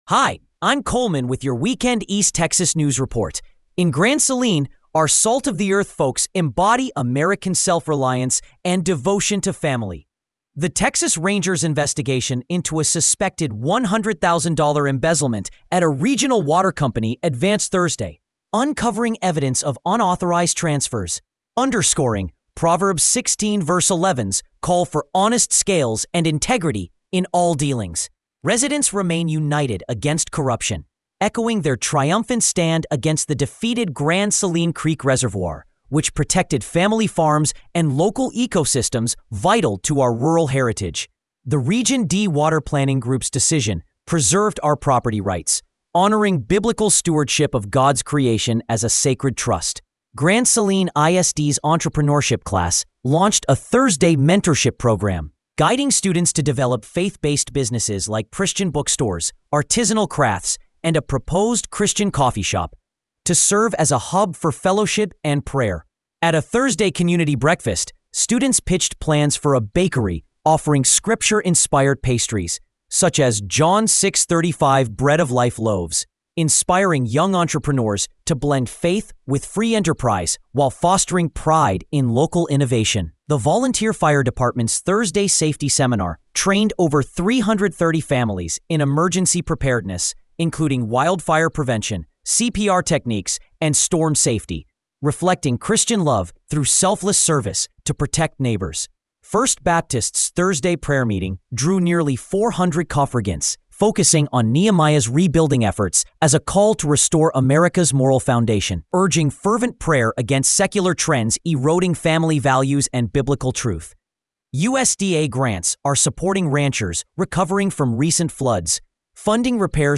Weekend East Texas News Report